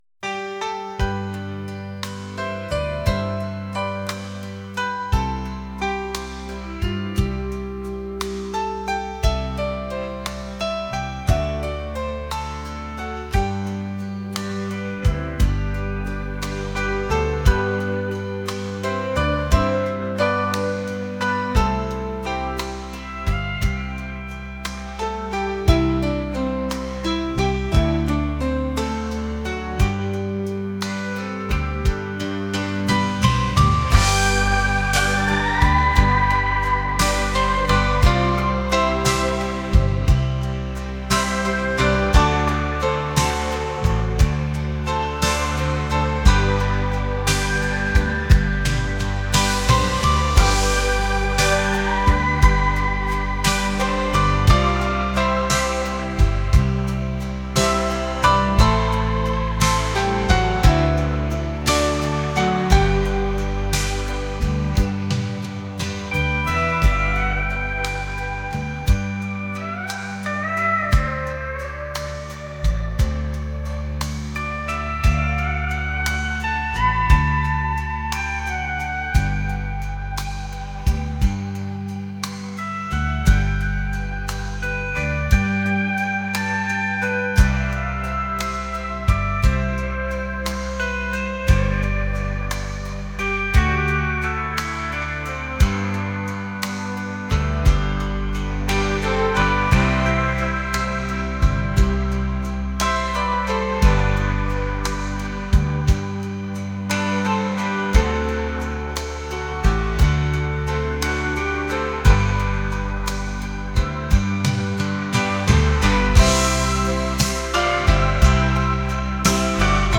rock | acoustic | soul & rnb